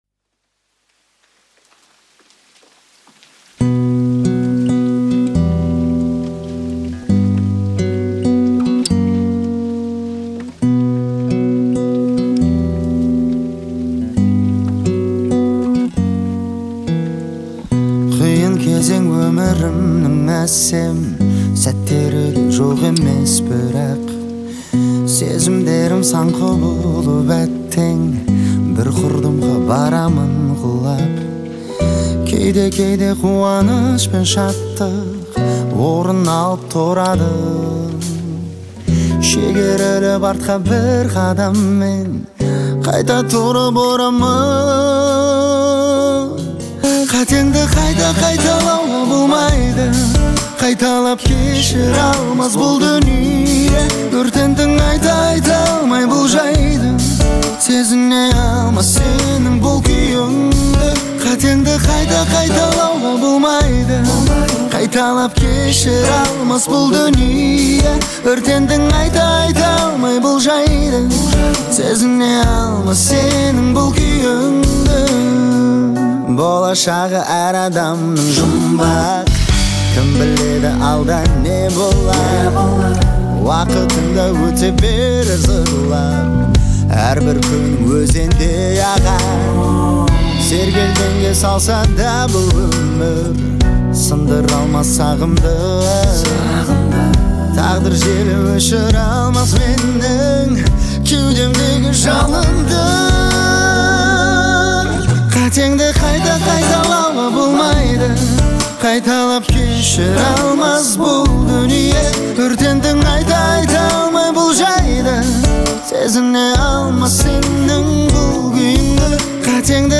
эмоциональный трек в жанре поп с элементами R&B